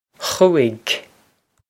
Khoo-ig
This is an approximate phonetic pronunciation of the phrase.